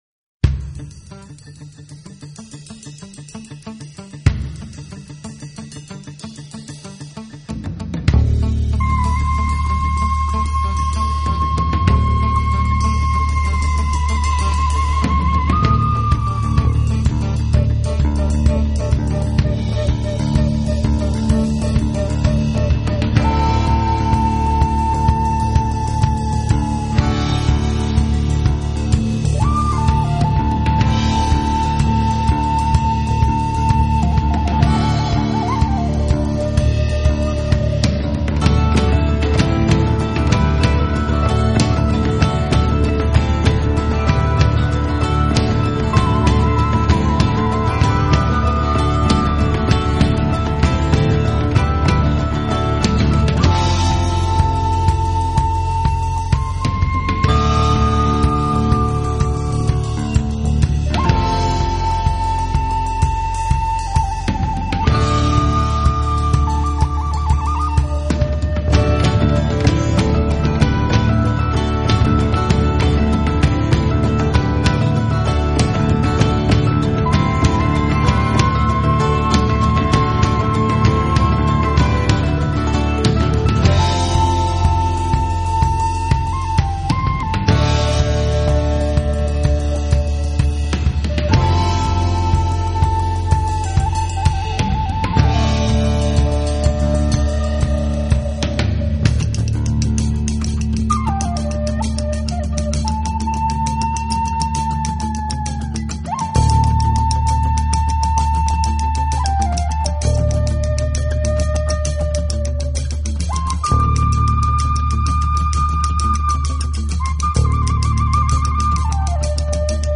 is an uplifting booty shaker